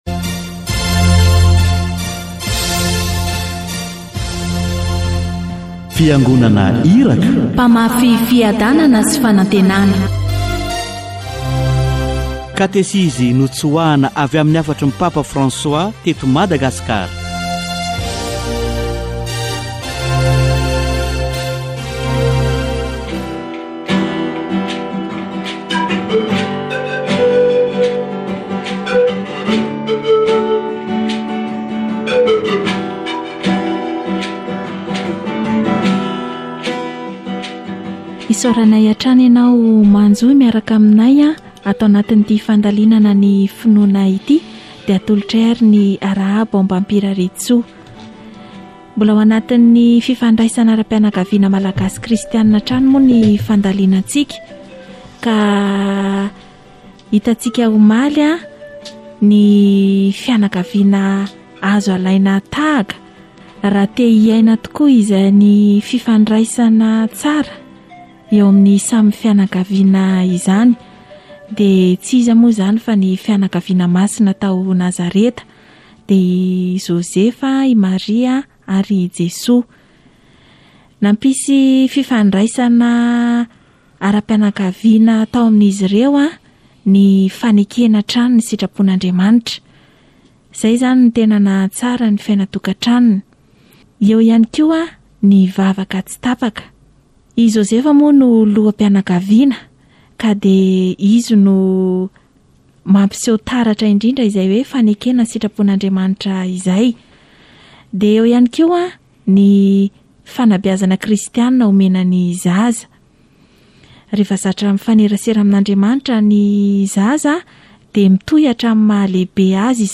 The Christian spirit needs to prevail in the family in order to develop the relationship that exists between individuals. Catechesis on the family